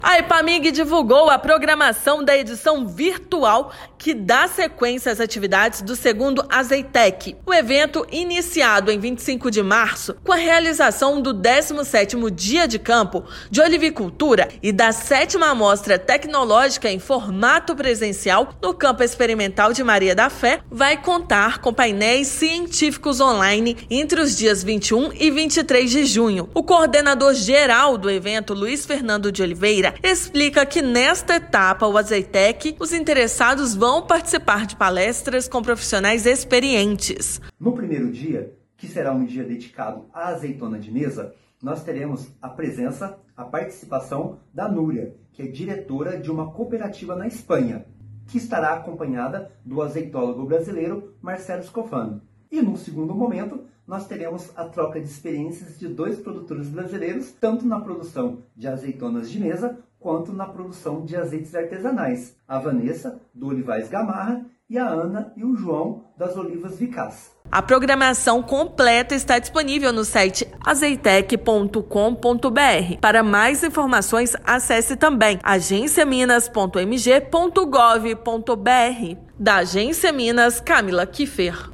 Etapa virtual será de 21 a 23/6, com a participação de especialistas do Brasil e do exterior. Ouça a matéria de rádio.